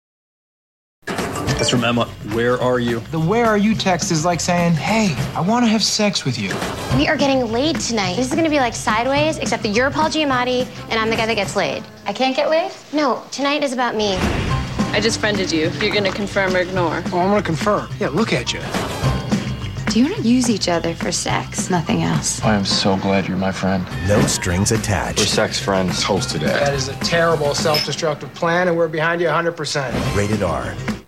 No Strings Attached TV Spots